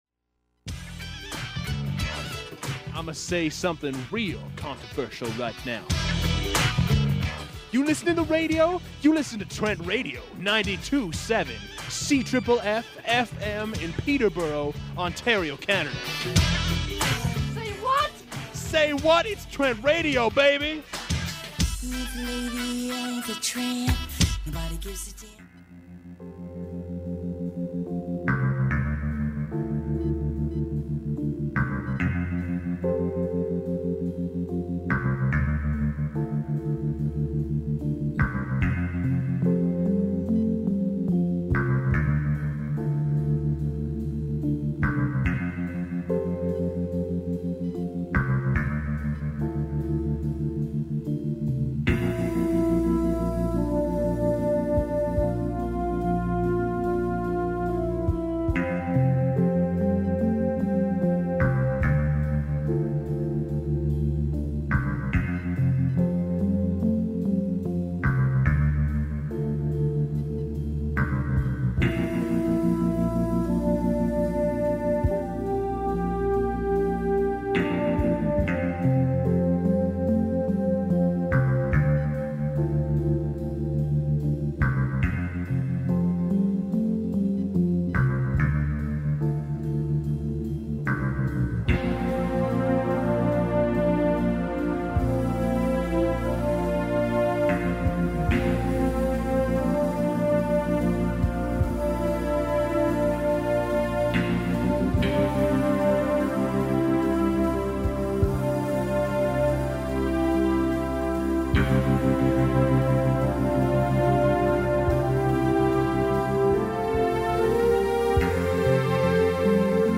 You’re listening to Cinesthesia, produced live in the studio at Trent Radio 92.7 CFFF FM in beautiful (Nogojiwanong) Peterborough, Ontario, every Thursday at 5 PM.